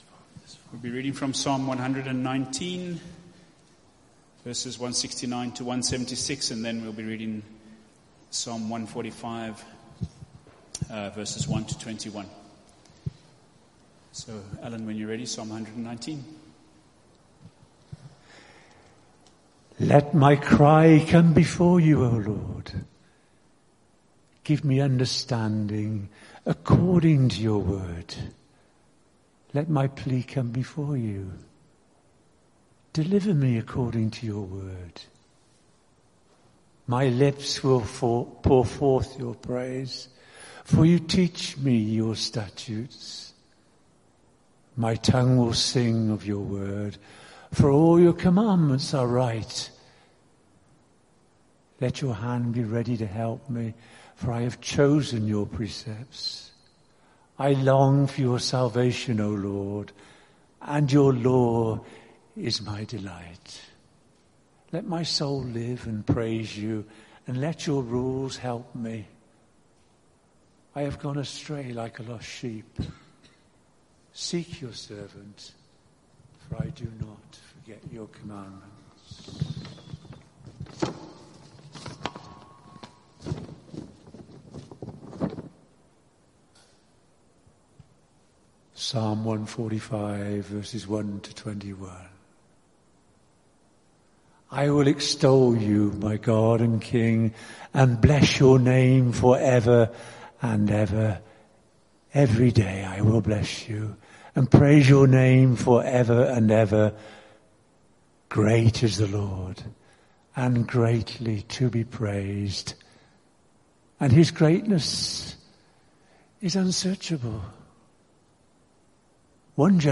Sermon Series: Taming the Tongue